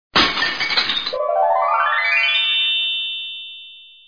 SFX砸金蛋中奖音效下载
SFX音效